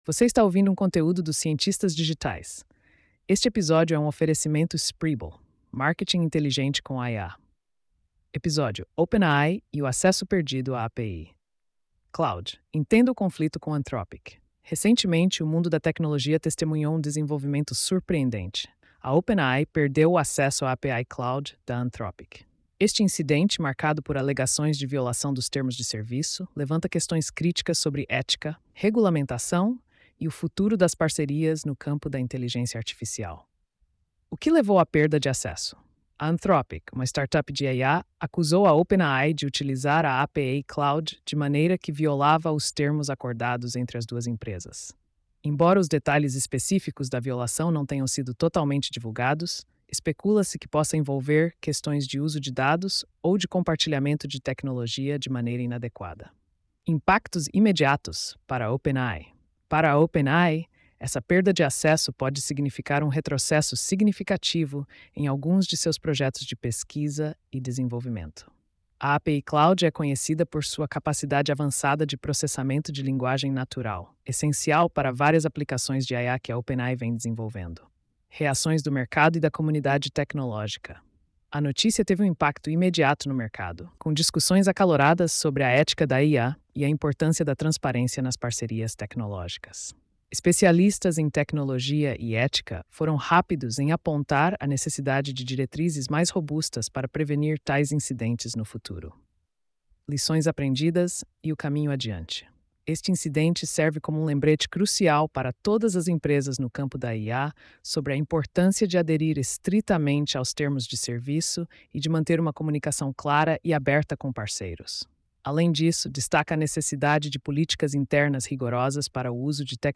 post-4152-tts.mp3